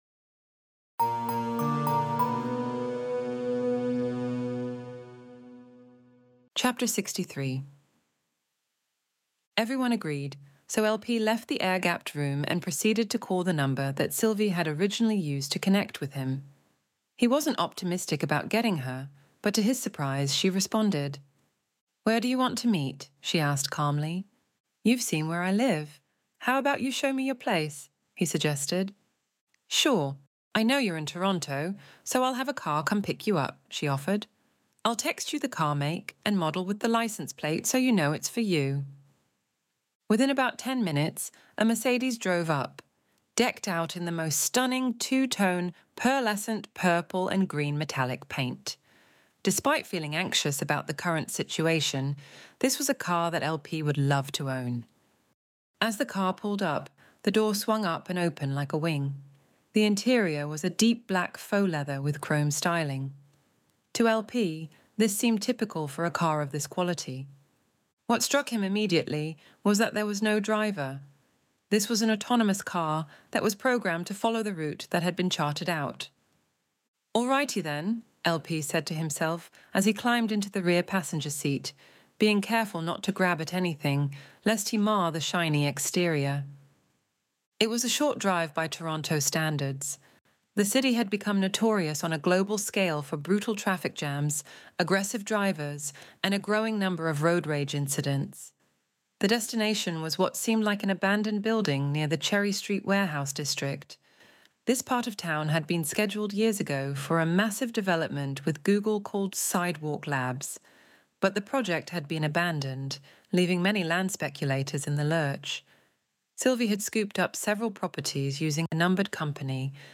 Extinction Event Audiobook Chapter 63